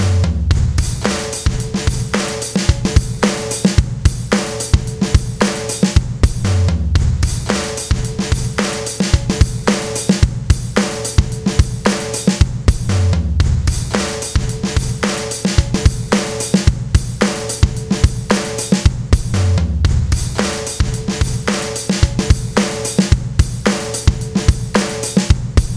Тут первым уад, за ним сразу FGR. И так 4 цикла.